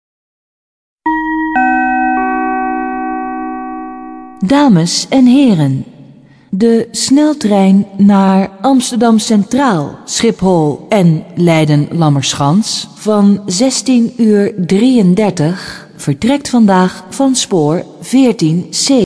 Nee stationsomroep.